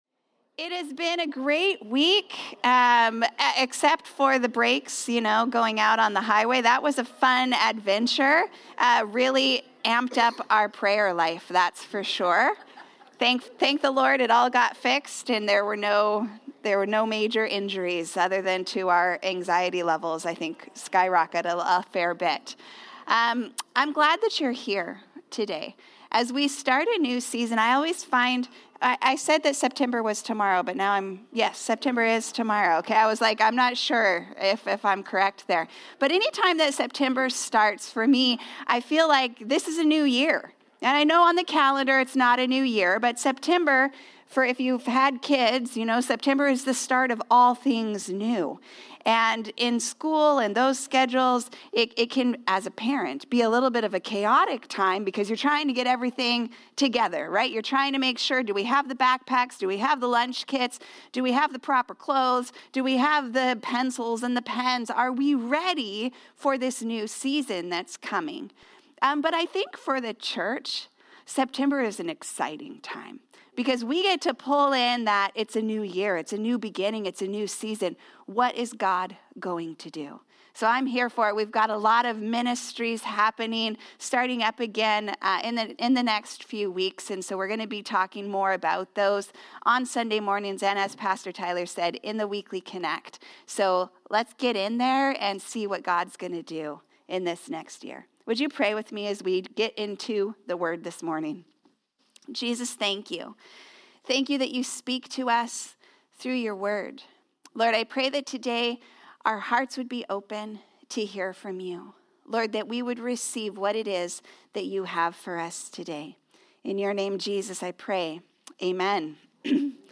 Sermons | Harvest Church